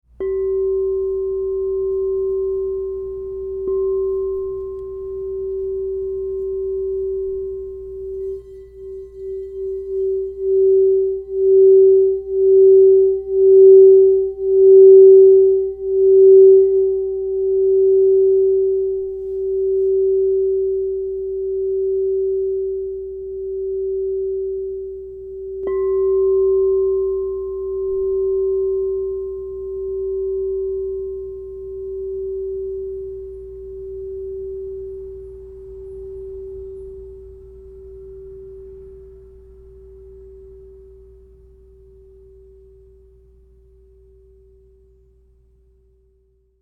Crystal Tones® Diamond Round Bottom 7 Inch G Singing Bowl
Experience the radiant energy of the Crystal Tones® Diamond Round Bottom 7 inch G Singing Bowl, resonating at G -35 to inspire clarity, transformation, and spiritual enlightenment.
The 7-inch round-bottom design enhances the bowl’s rich, resonant tones, making it ideal for personal meditation, sound therapy, and sacred rituals.
432Hz (-)